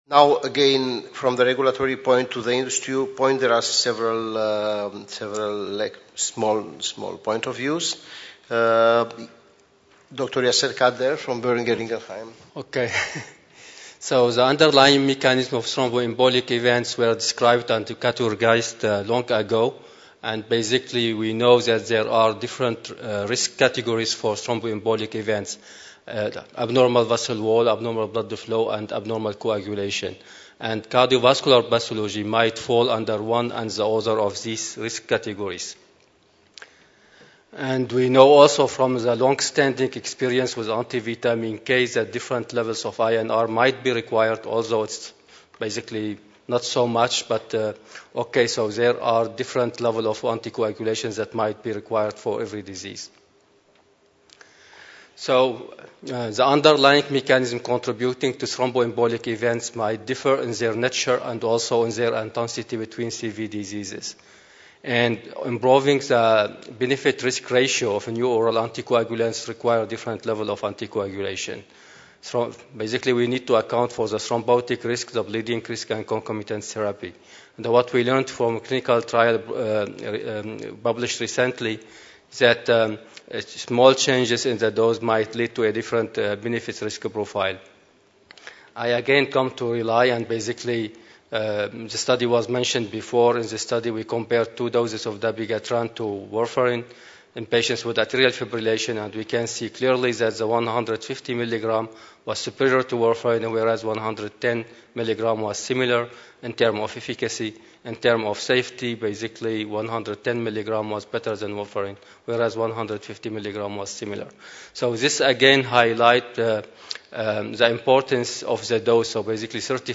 THE THROMBOSIS TRIALISTS WORKSHOP